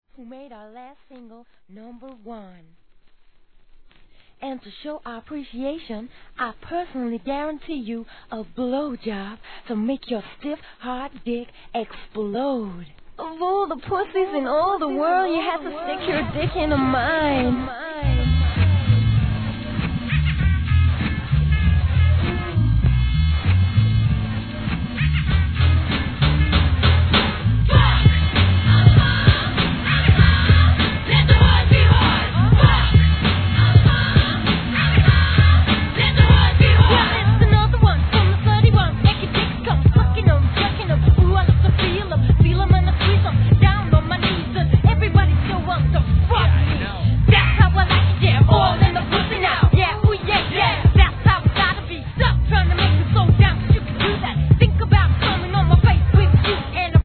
HIP HOP/R&B
いかしたジャケットにイケイケの馬鹿さ加減はお父様には聞かせられません。